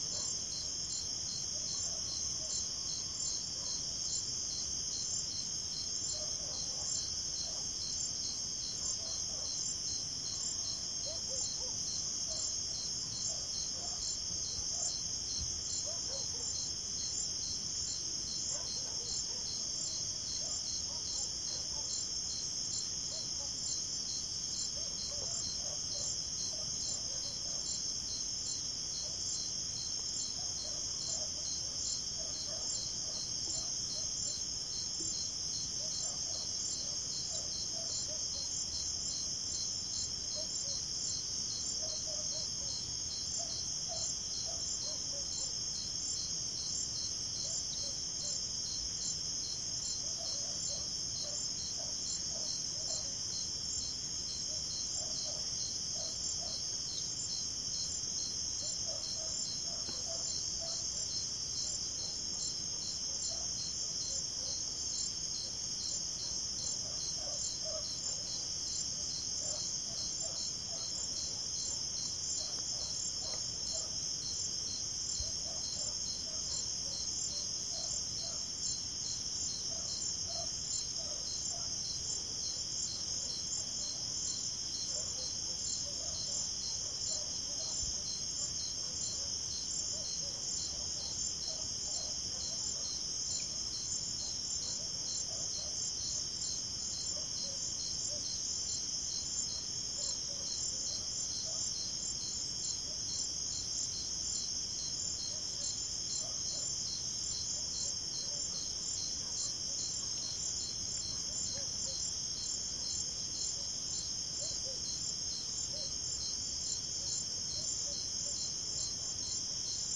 Osúnicos'sonsque se ouvia eram os dos grilos latidos longe。
你能听到的唯一声音是蟋蟀和吠叫声。
Tag: cachorros 晚上 蟋蟀 晚报 性质 现场录音 动物